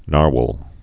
(närwəl) or nar·whale (-wāl, -hwāl)